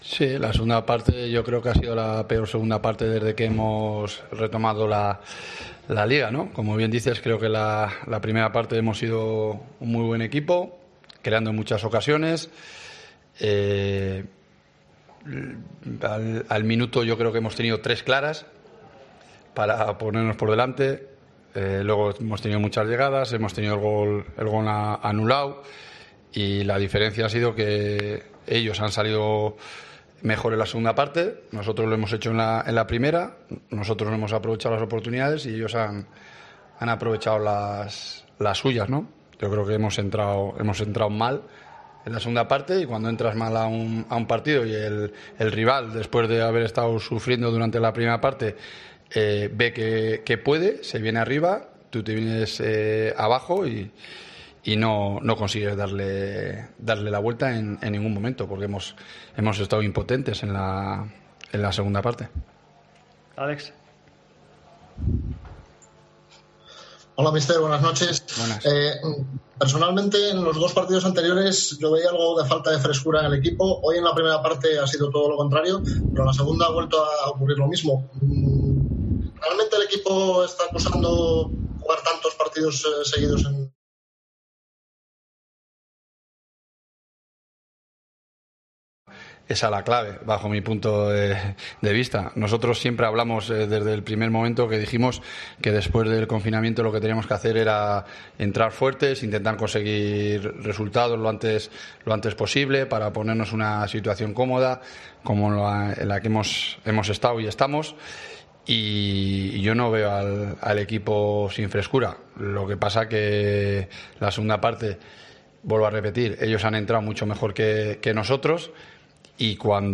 Escucha aquí al entrenador de la Deportiva Ponferradina tras la derrota en El Toralín 0-3 ante el Fuenabrada